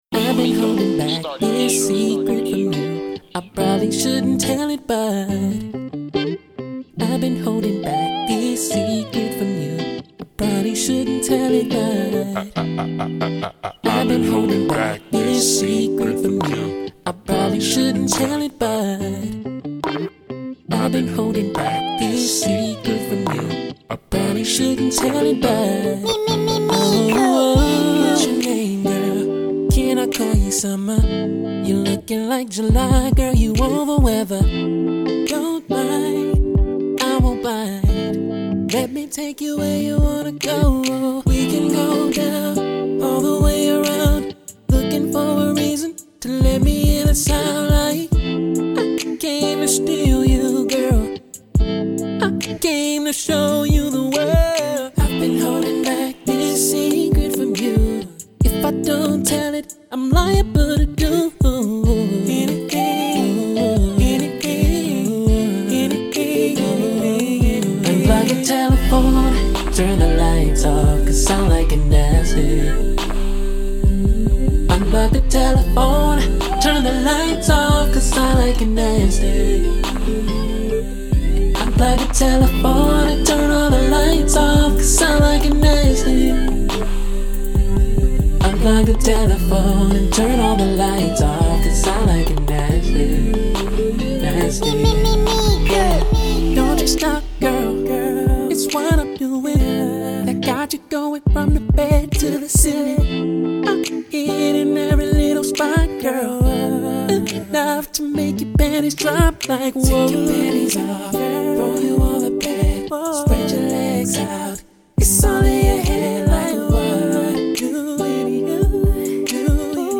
The US-based R&B/Pop crooner
high pitched crooner